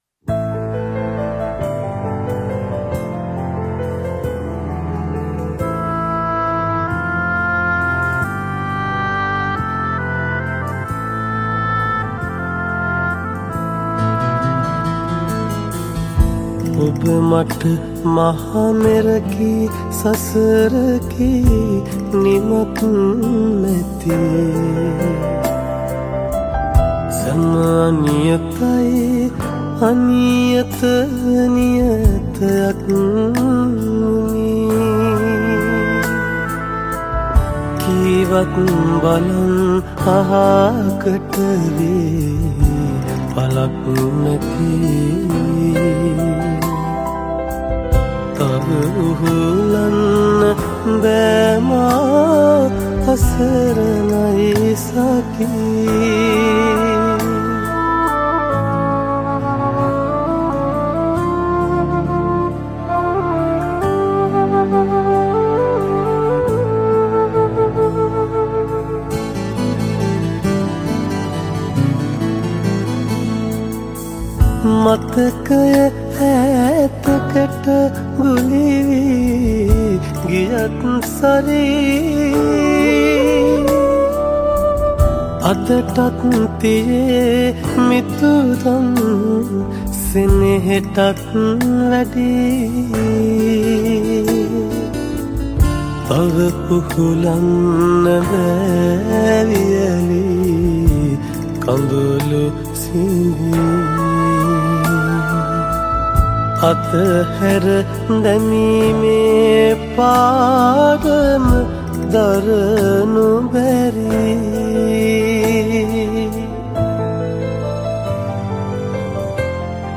Sinhala Song
Emotional Sinhala Song